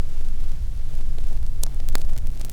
cch_fx_one_shot_crackle.wav